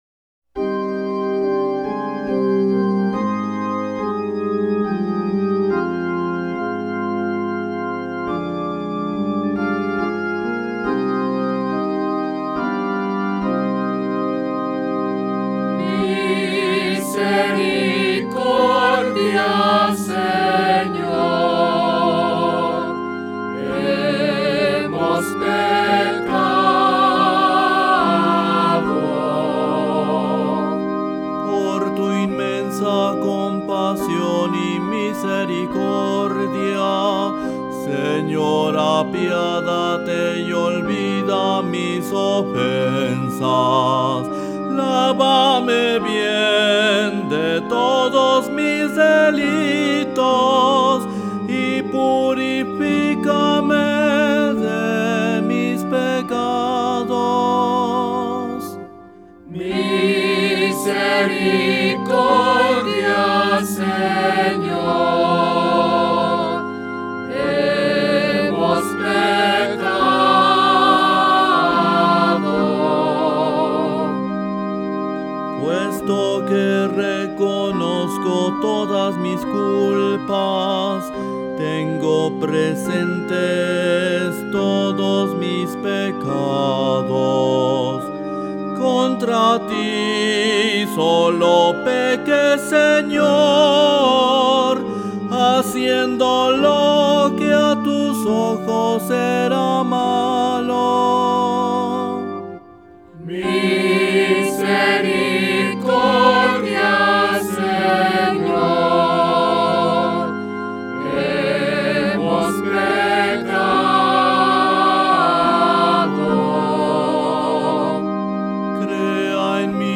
SALMO RESPONSORIAL Del salmo 50 R. Misericordia, Señor, hemos pecado.